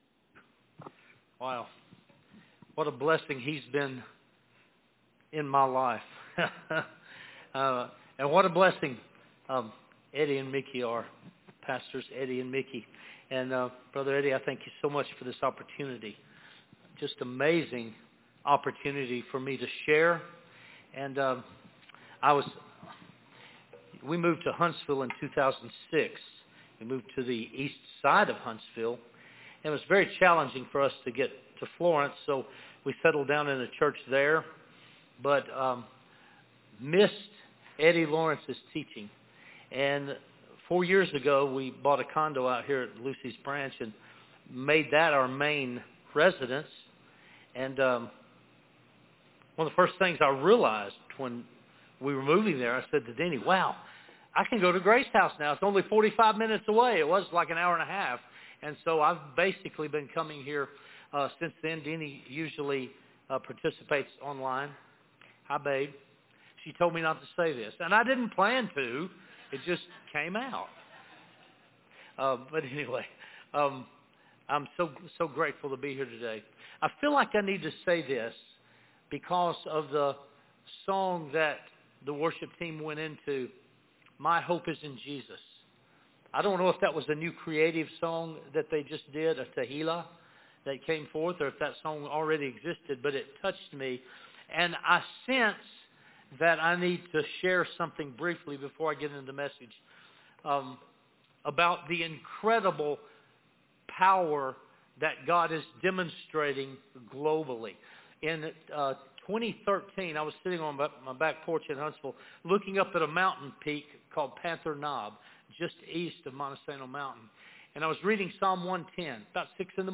Sermons & Messages